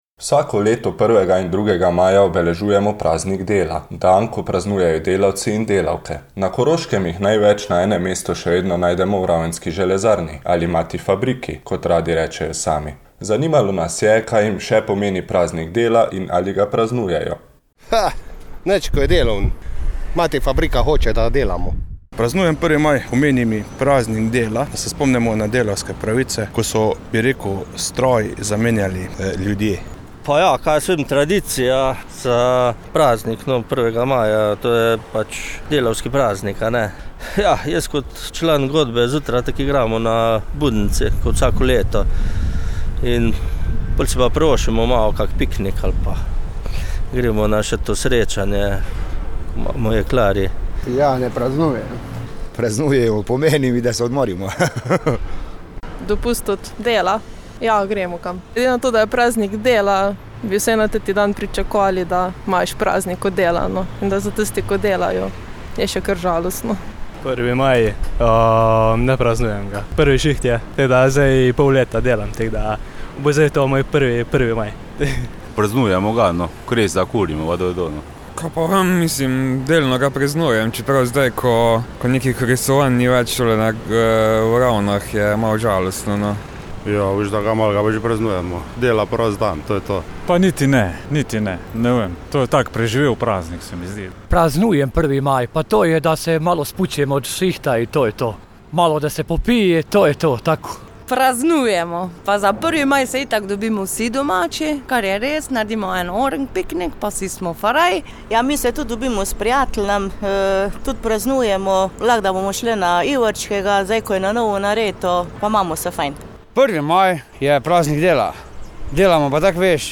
Anketa med železarji